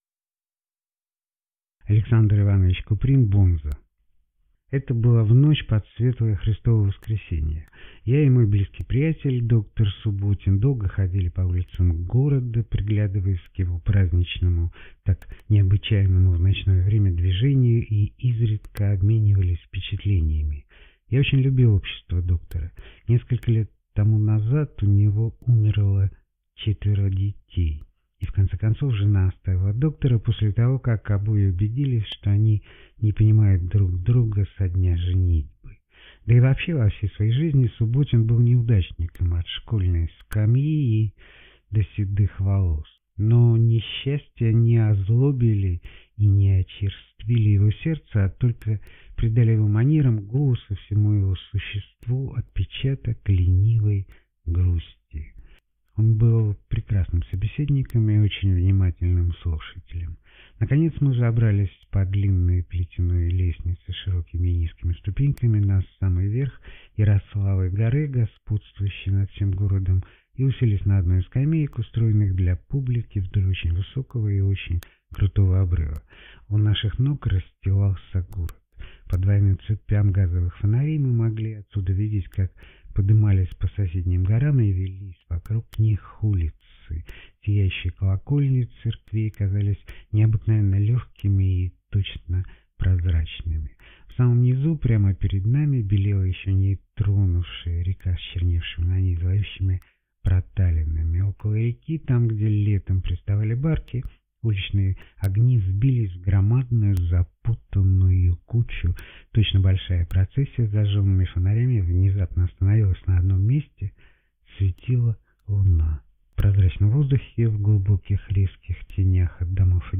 Aудиокнига Бонза